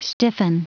Prononciation du mot stiffen en anglais (fichier audio)
Prononciation du mot : stiffen
stiffen.wav